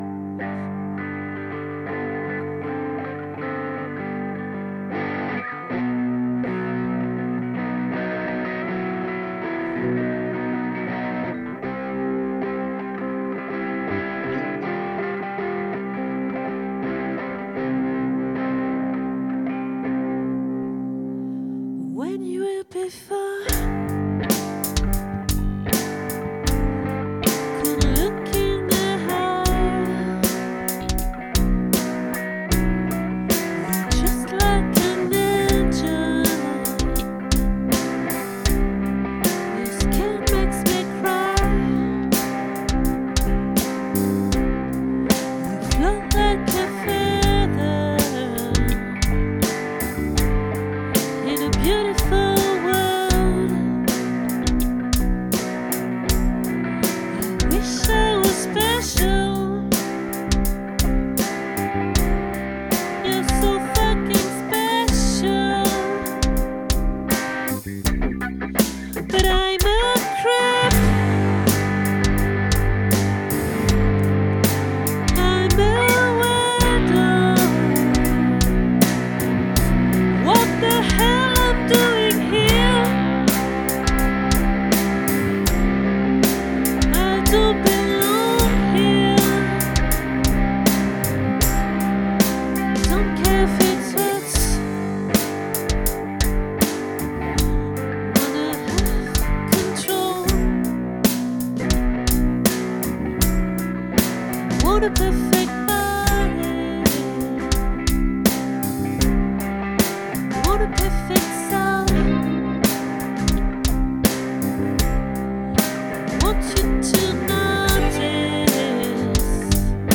🏠 Accueil Repetitions Records_2025_01_13